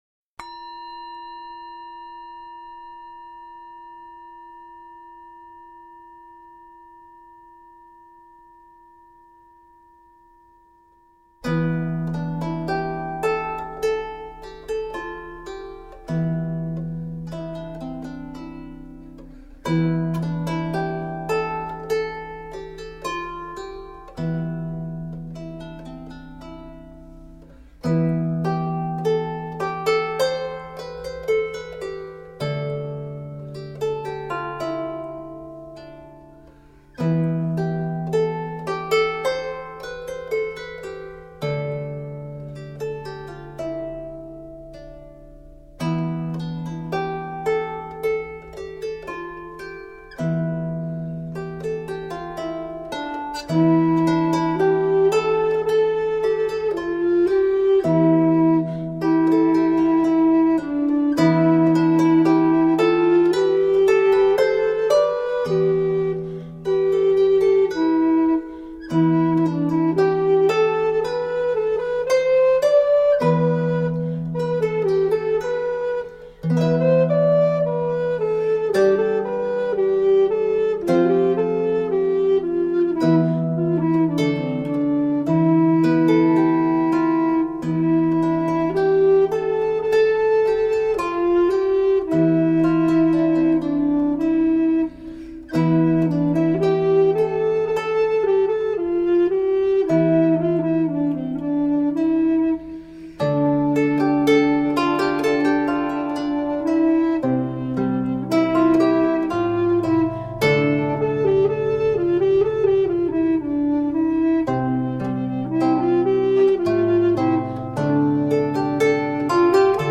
Early music for healing.